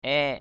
/ɛ:ʔ/ 1.